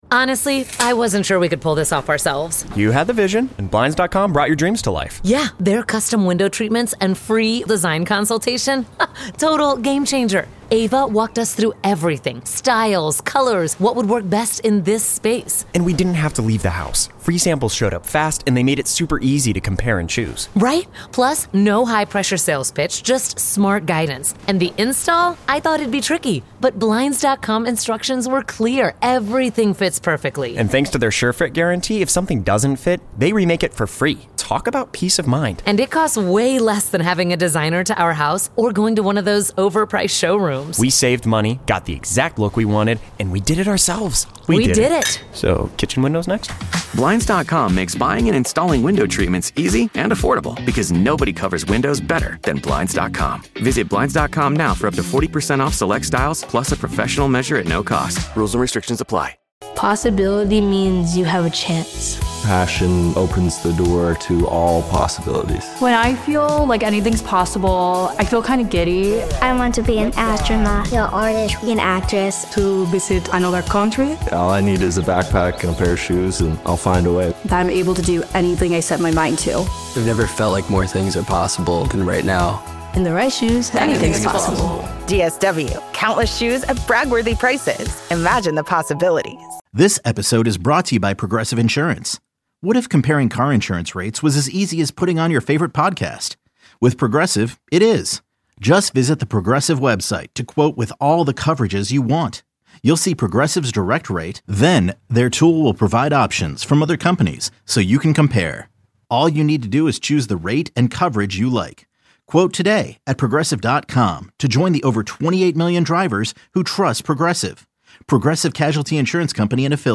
A live post-show that airs twice per week (Tuesdays and Thursdays)